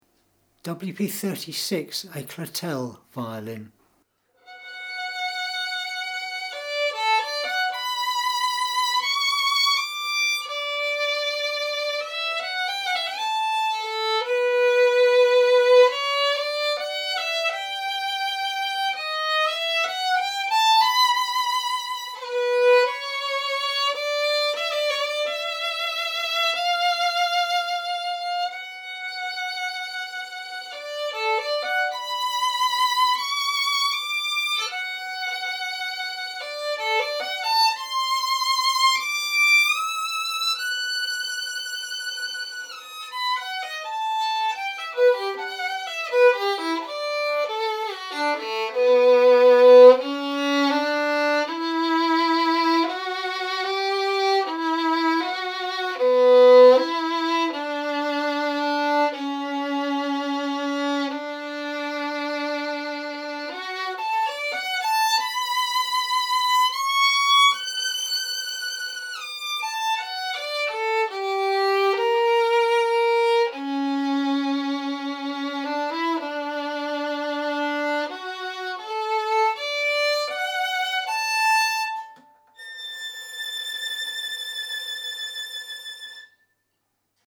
A A Clotelle violin, circa 1925.